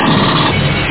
CHAINGUN.mp3